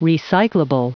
Prononciation du mot : recyclable
recyclable.wav